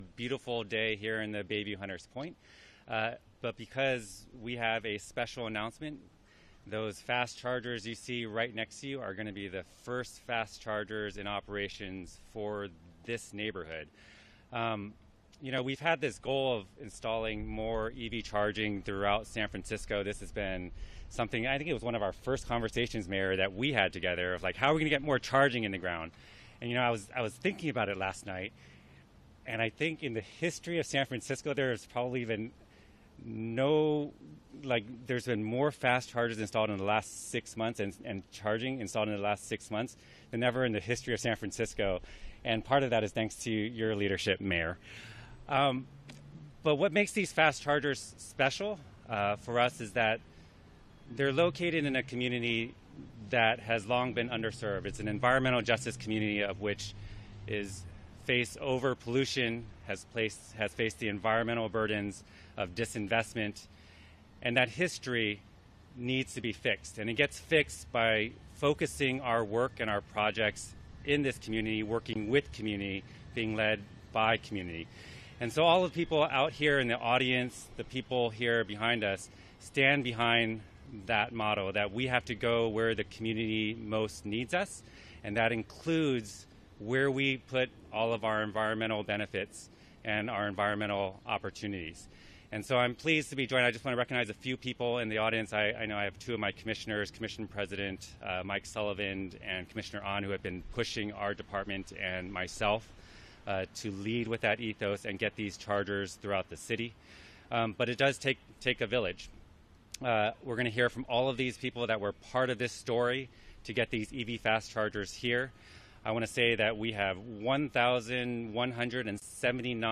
Electric vehicle charger ribbon-cutting - Jul 09, 2025
City and County of San Francisco: Mayor's Press Conference